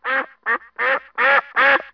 دانلود صدای حیوانات جنگلی 39 از ساعد نیوز با لینک مستقیم و کیفیت بالا
جلوه های صوتی